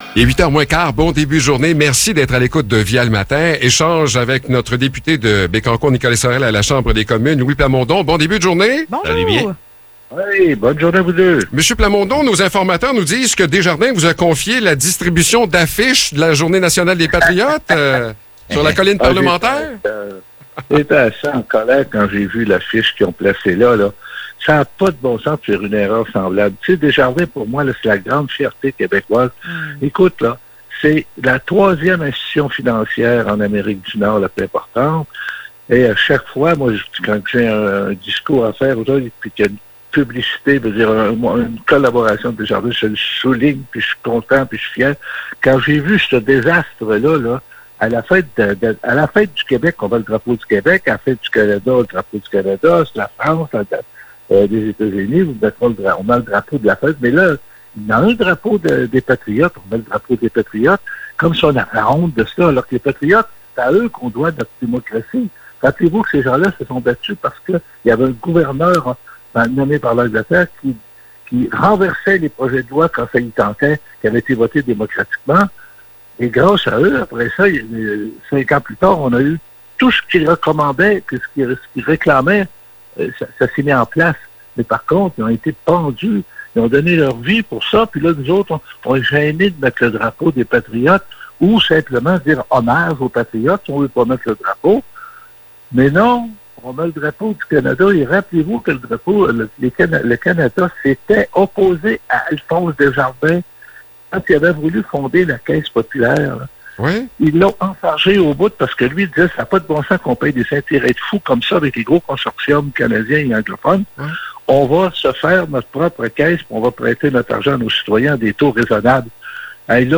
Échange avec Louis Plamondon
Louis Plamondon est député de Nicolet Bécancour Saurel à la Chambre des communes. Il revient sur l’erreur de Desjardins concernant la fête des Patriotes.